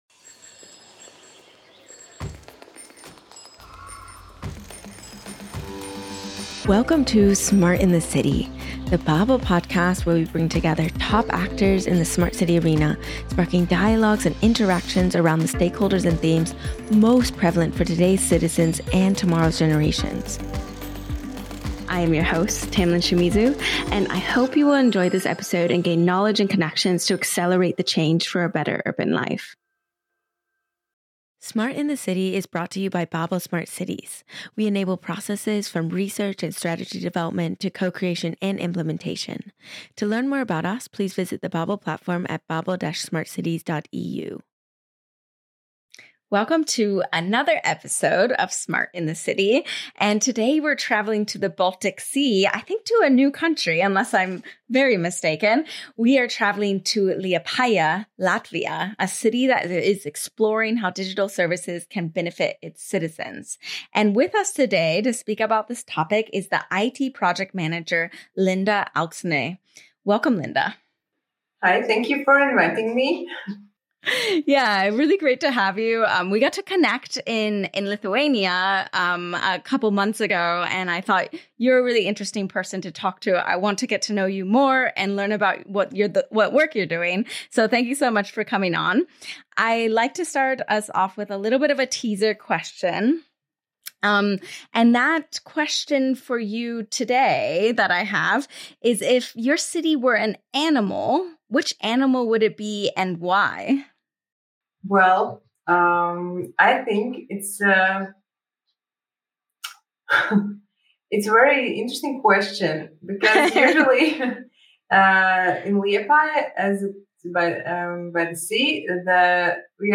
The conversation tracks lessons from an Interact Europe open-data project, early steps toward a digital-twin starting with energy consumption visualisation in municipal buildings, and inclusive approaches that keep non-digital users engaged through trainings and traditional channels.